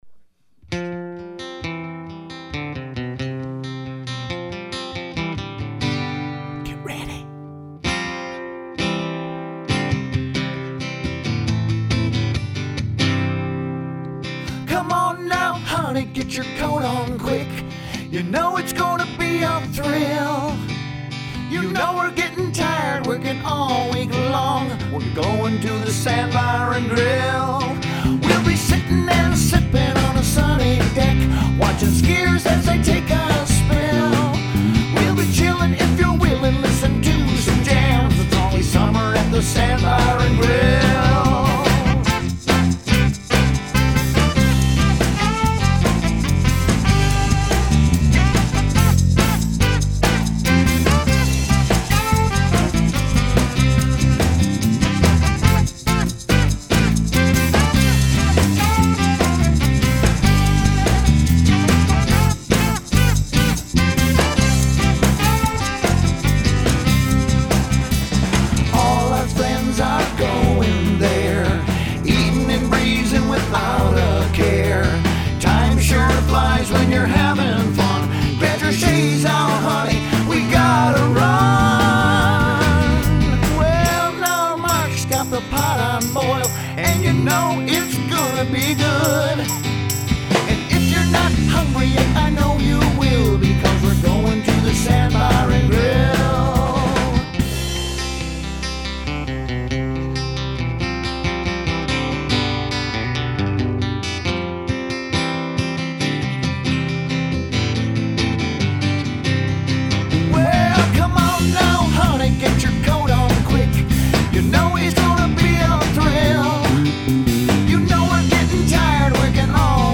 Sandbar and Grill (Jimmy Buffet stylings...)
Vocals, Guitars, Saxophones and Percussion
Bass Guitar
Drums
Produced and recorded at Lachine Machine Sound Studios.